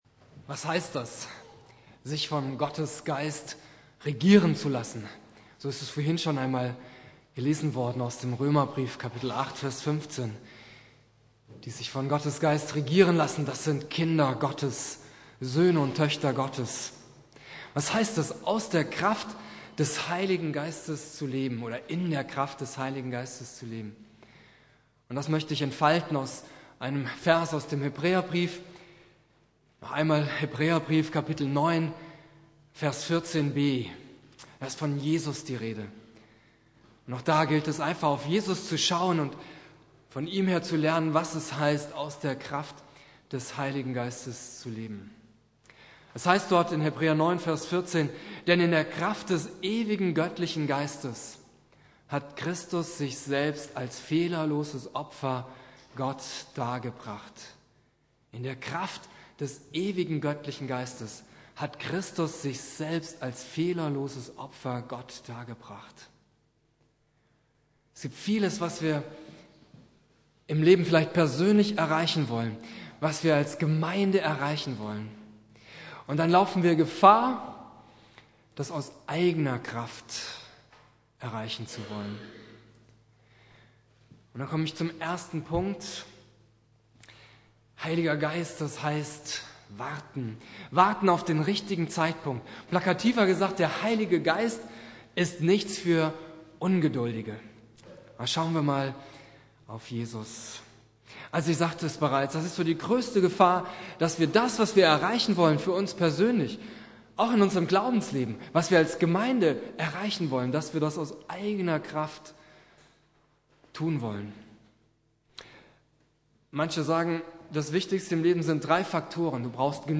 Predigt
Pfingstsonntag Prediger